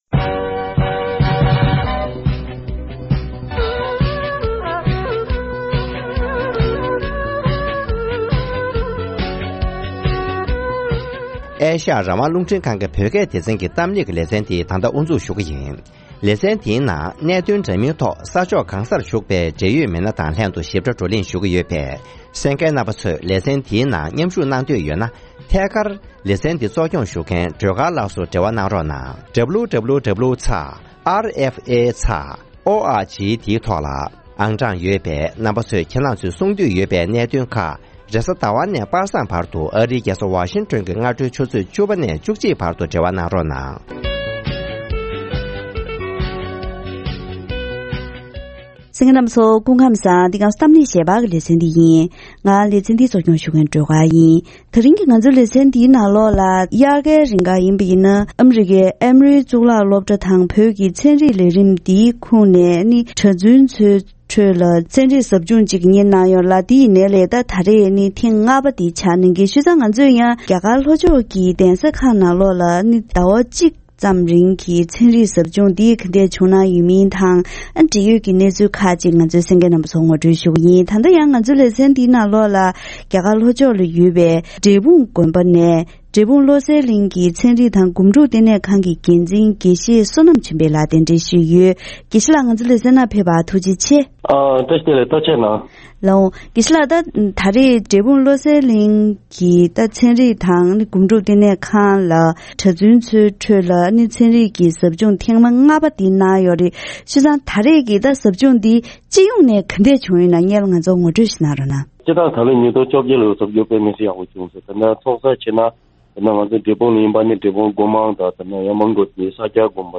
ཐེངས་འདིའི་གཏམ་གླེང་ཞལ་པར་ལེ་ཚན་ནང་།